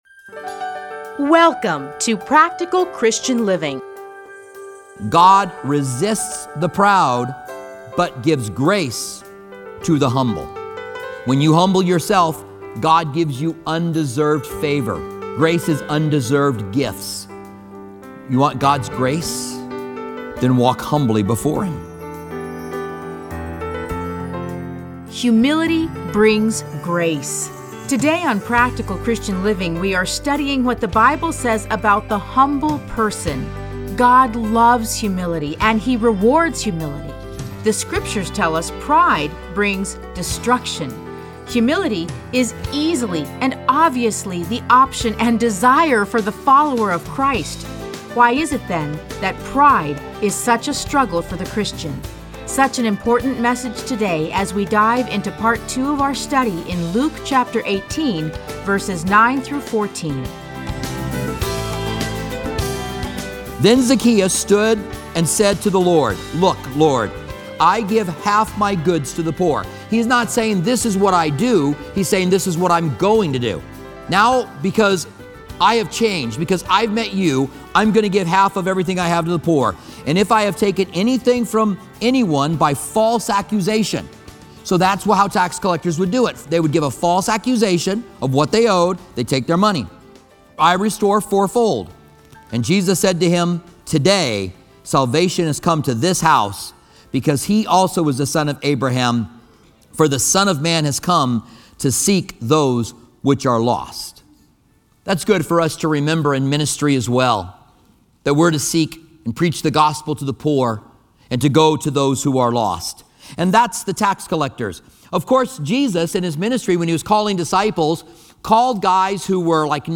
Listen to a teaching from Luke 18:9-14.